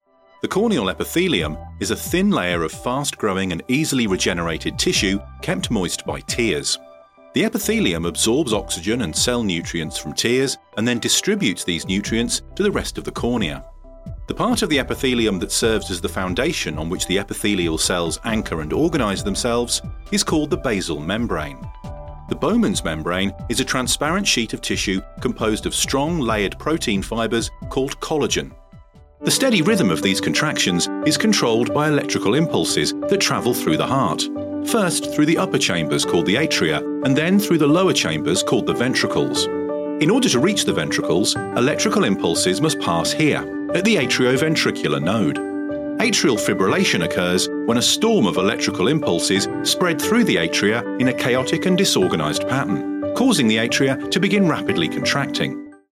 English (British)
Medical Narration
Mic: SHURE SM7B